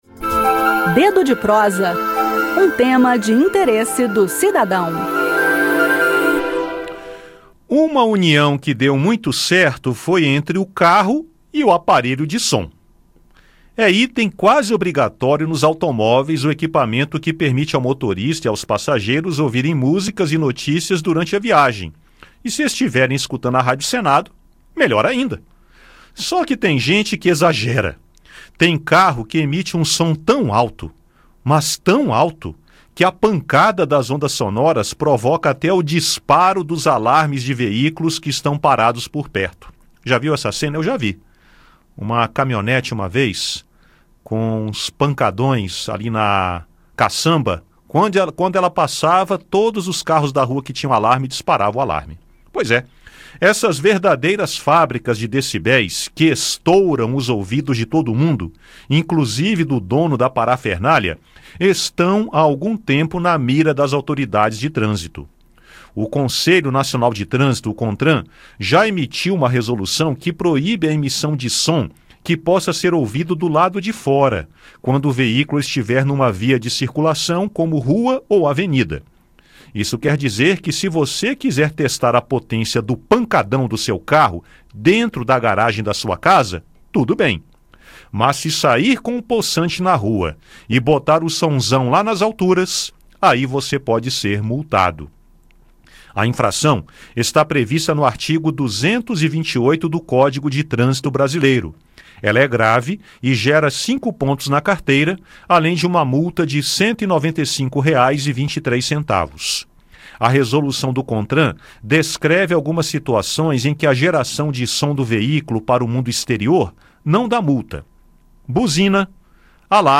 Confira o bate-papo